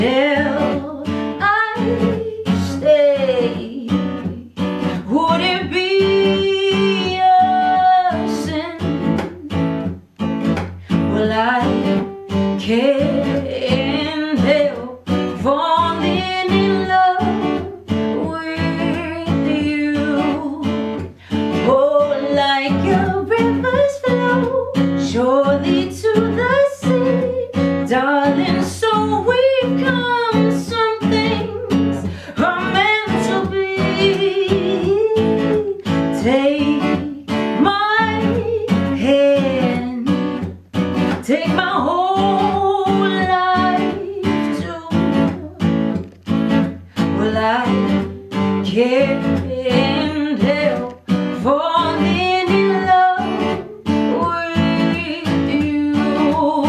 Soul, Rock und Pop
LIVE ACOUSTIC COVER